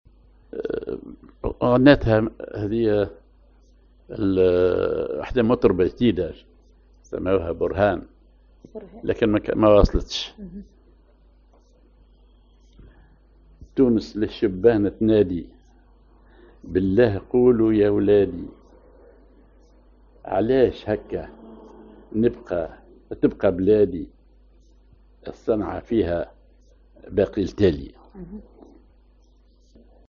نهاوند (بوسلك على درجة الدوكاه)
أغنية